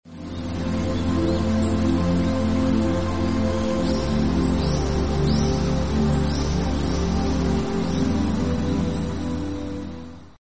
Amid the lush tropical forest, sound effects free download
Mp3 Sound Effect Amid the lush tropical forest, a small waterfall gently cascades, spreading a serene and magical calm.